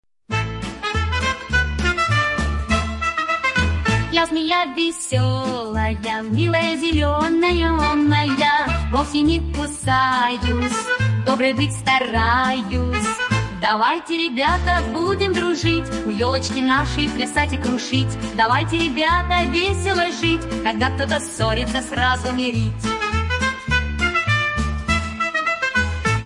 Детская песенка на Новый Год Змеи
Фрагмент 1-го варианта исполнения: